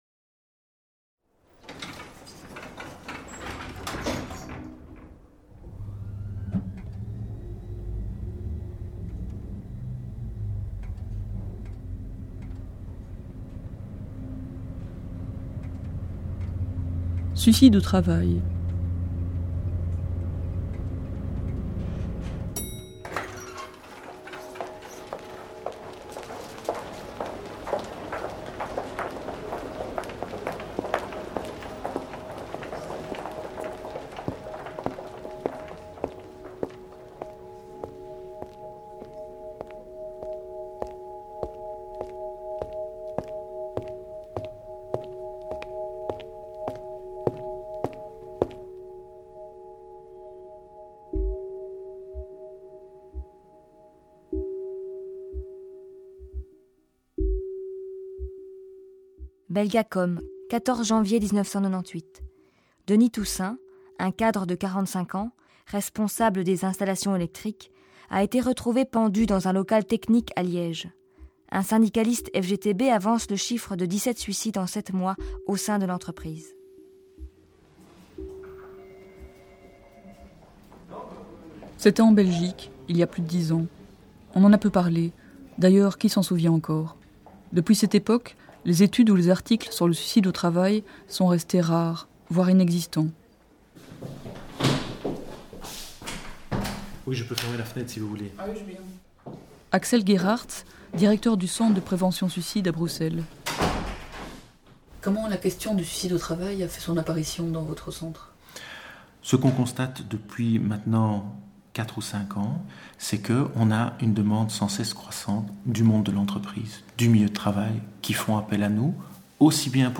Suicides au travail se développe à travers trois matériaux principaux. D’abord, la parole de quatre interlocuteurs qui sont confrontés à cette question du suicide au travail dans leur pratique professionnelle. Puis, tel un leitmotiv, l’énumération de brèves journalistiques évoque une situation générale dramatique. Enfin, en contrepoint, les sons d’espaces collectifs de travail et les éléments musicaux qui font résonner les soubassements de ce monde du travail et semblent signifier que personne n’est à l’abri.